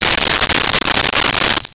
Key Movement 02.wav